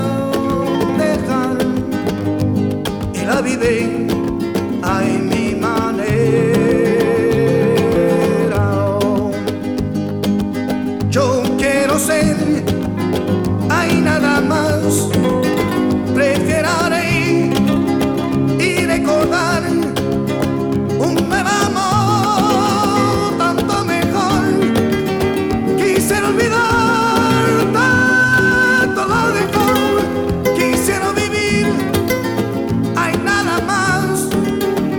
Жанр: Музыка мира